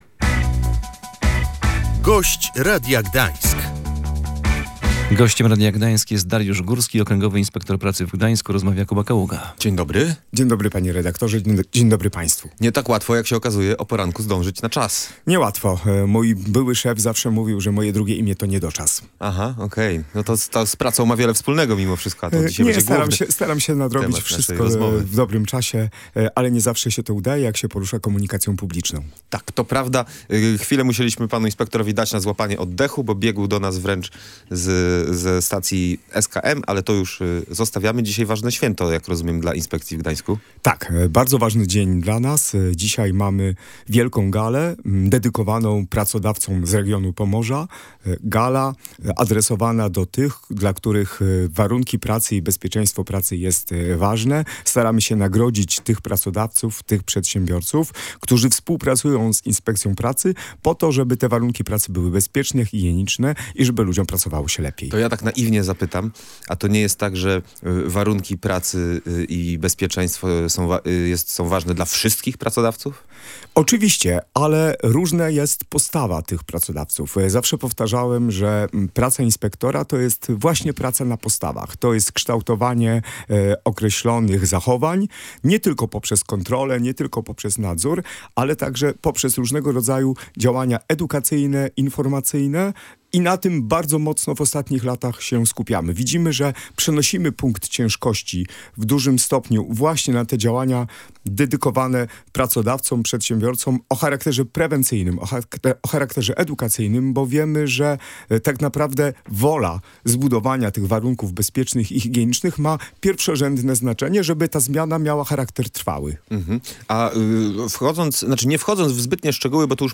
Wciąż zdarzają się skrajne sytuacje, podczas których prawo pracy nie jest przestrzegane – mówił w Radiu Gdańsk Dariusz Górski, Okręgowy Inspektor Pracy w Gdańsku. Najwięcej skarg pracowników zgłaszanych do inspektoratu dotyczy problemów z wypłatą wynagrodzenia.